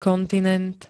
kontinent [-t-n-] -tu pl. N -ty I -tmi m.